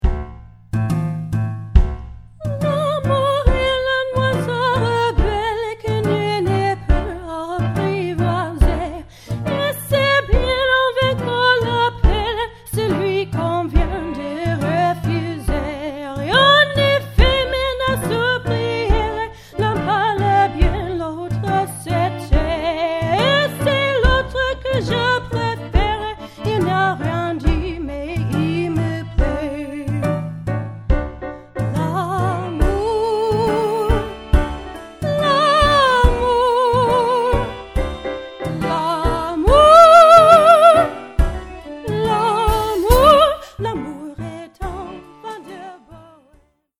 opera